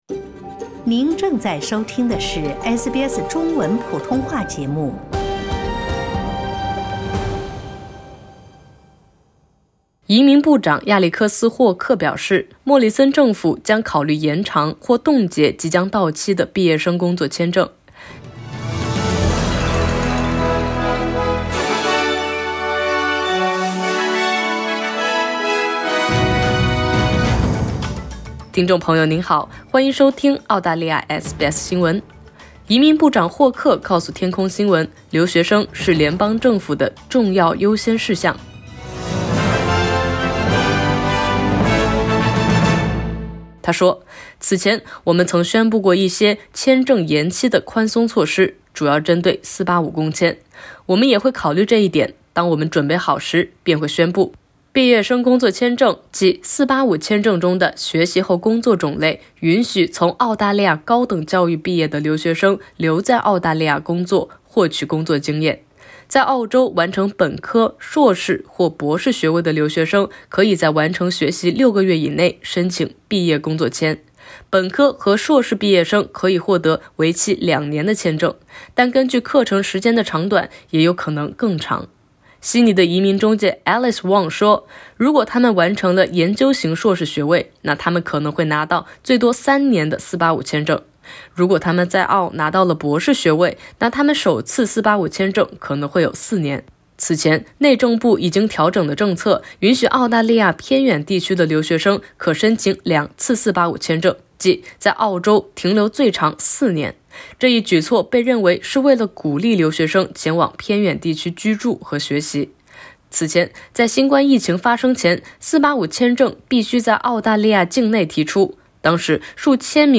移民部长亚历克斯·霍克（Alex Hawke）表示，莫里森政府将考虑延长或冻结即将到期的毕业生工作签证。此前，内政部已经调整了政策，允许澳大利亚偏远地区的留学生可申请两次485签证，即在澳大利亚停留最长四年。（点击上图收听报道）